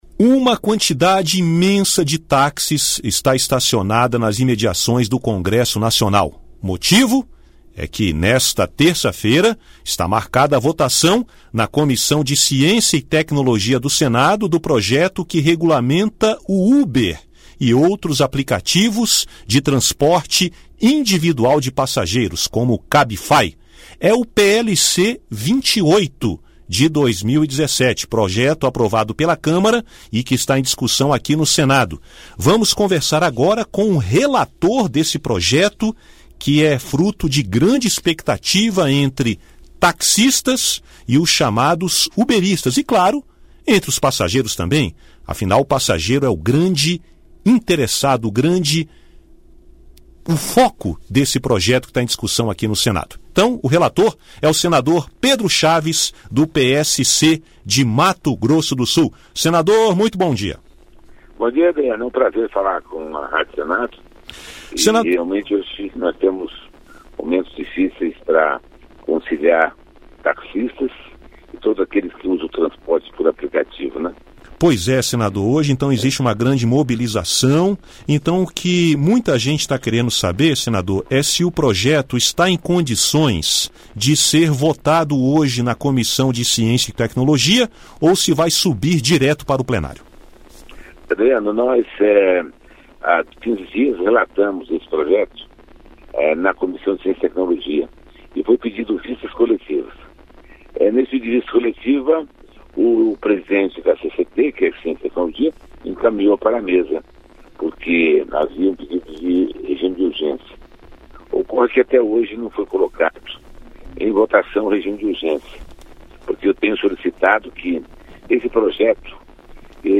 Relator dos projetos que regulamentam os aplicativos de transporte individual de passageiros, como o Uber, o senador Pedro Chaves (PSC-MS) falou nesta terça-feira (24), em entrevista à Rádio Senado, das dificuldades para a votação do tema na Comissão de Ciência, Tecnologia, Inovação, Comunicação e Informática (CCT).